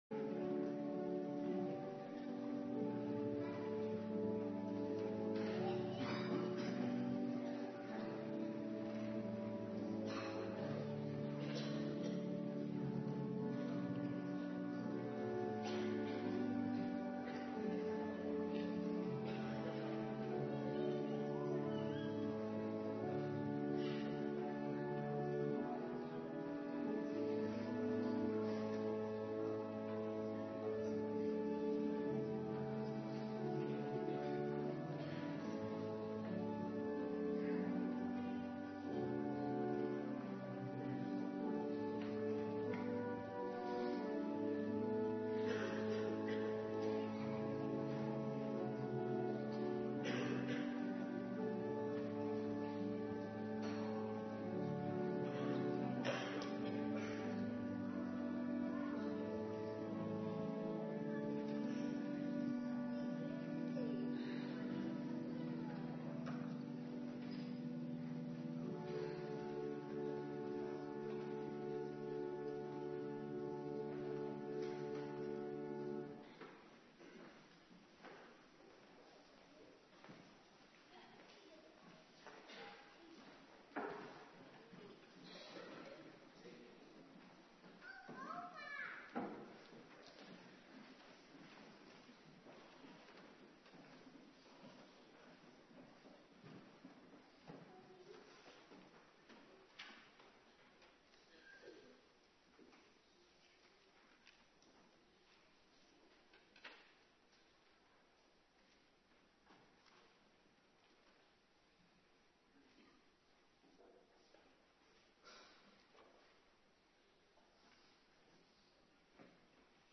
Morgendienst dankdag
09:30 t/m 11:00 Locatie: Hervormde Gemeente Waarder Agenda